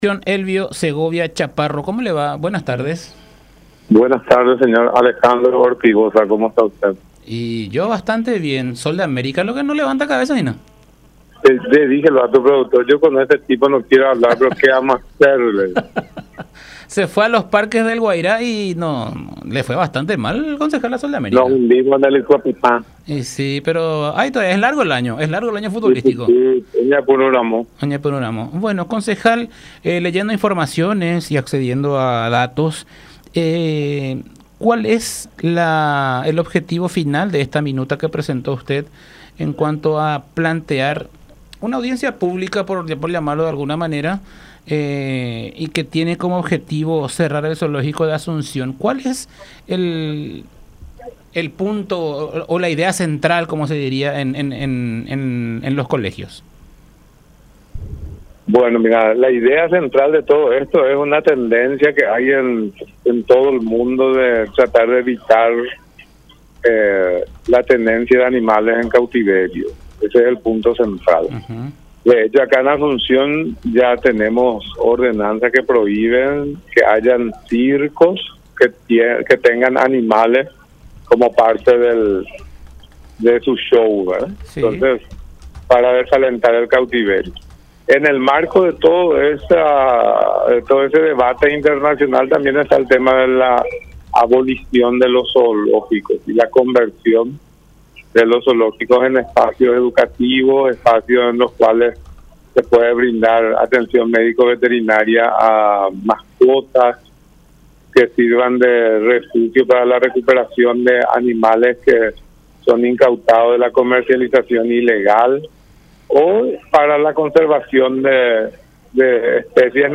“La idea central es tratar de evitar tener animales en cautiverio” indicó el Concejal Elvio Segovia en diálogo con La Unión R800AM.